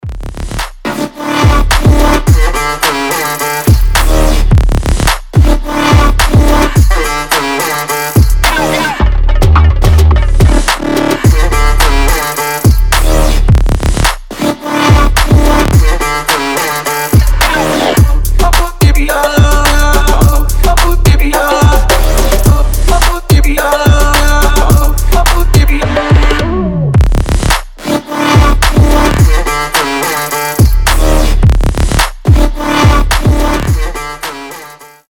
• Качество: 320, Stereo
громкие
жесткие
мощные
басы
восточные
индийские
Жесткий Hindustani trap